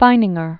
(fīnĭng-ər), Lyonel Charles Adrian 1871-1956.